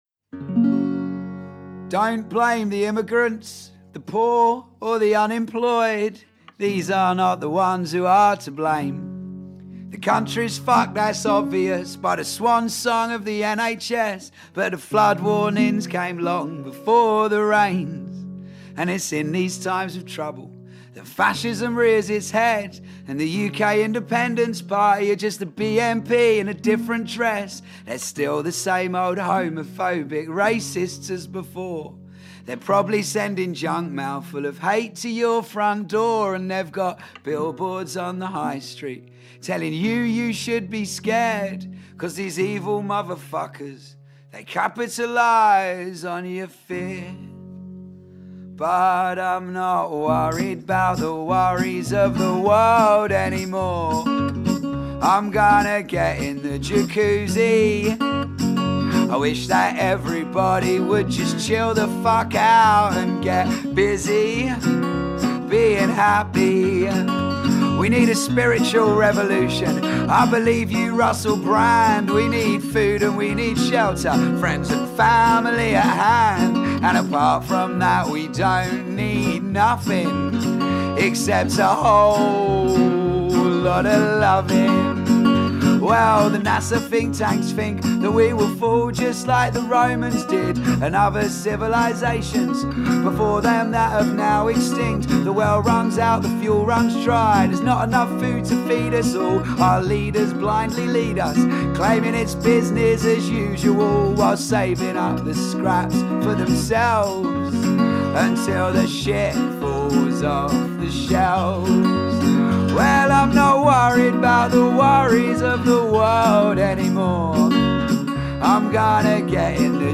Entrevista a Winona Riders y recuerdo a cantantes fallecidos 2025